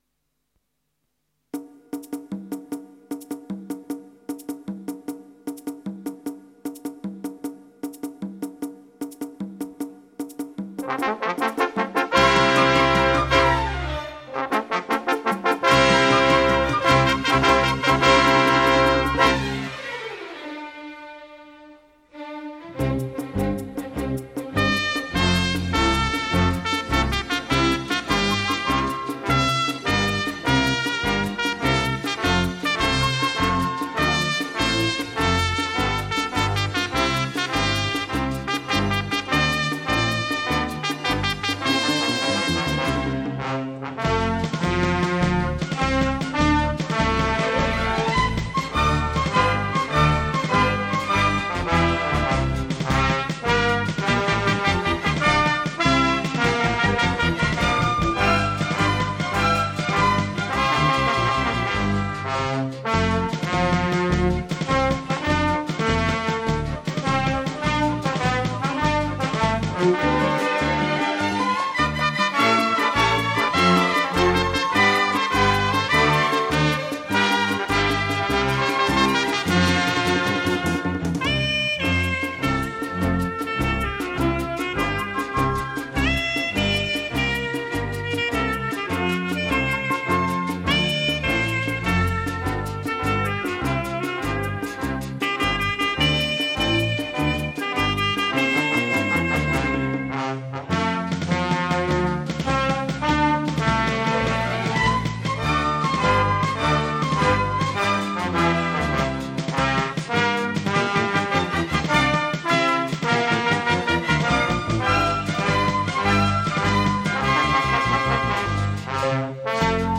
进行曲 March Band